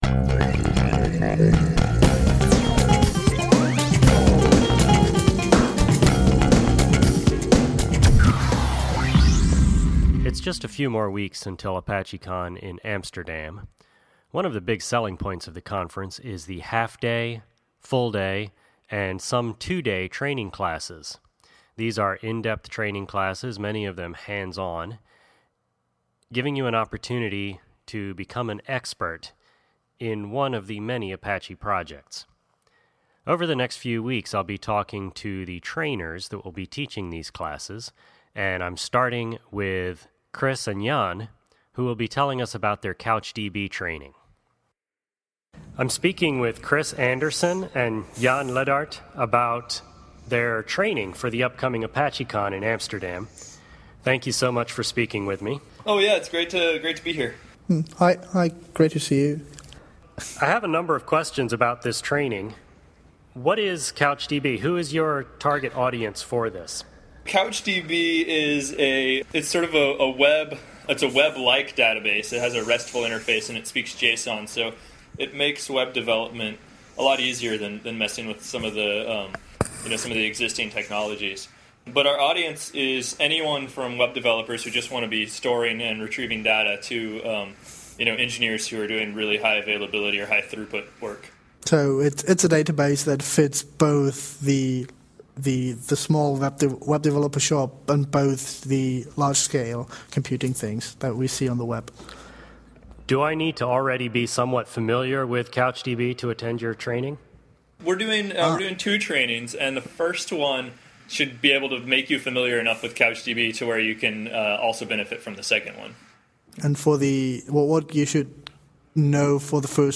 I spoke with them a few days ago about what their training will cover.